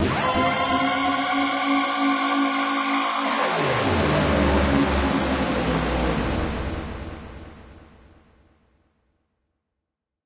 snd_fountain_make.ogg